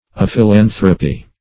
Search Result for " aphilanthropy" : The Collaborative International Dictionary of English v.0.48: Aphilanthropy \Aph`i*lan"thro*py\, n. [Gr.